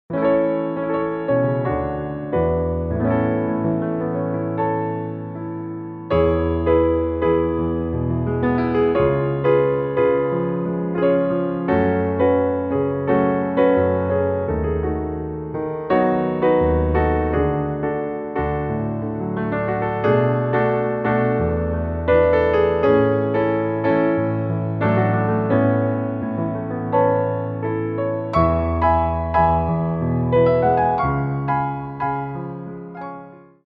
Piano Arrangements
Slow Tendus
4/4 (16x8)